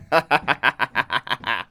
00547 man laugh 6 very bad
Category 🤣 Funny
bad derride evil laugh laughing laughter male man sound effect free sound royalty free Funny